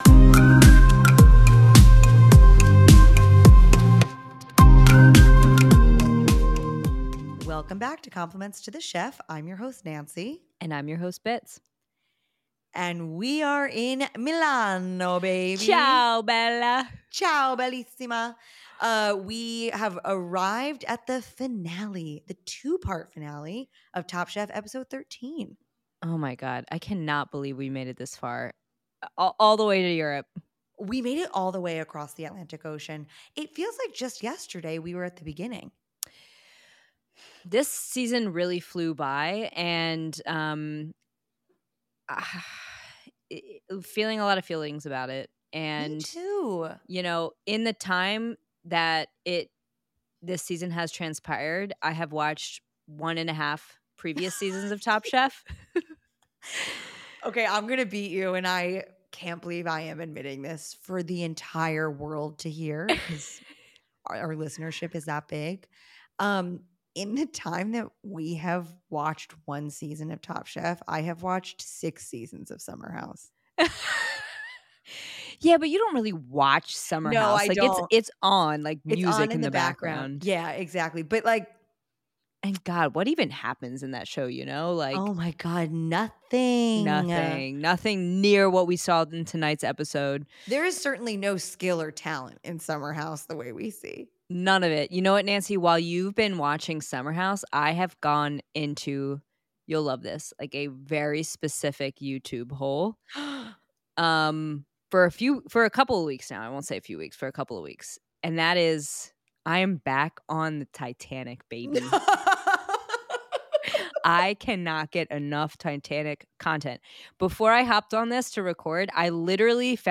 Bonus Interview